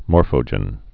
(môrfō-jĕn)